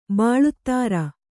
♪ bāḷuttāra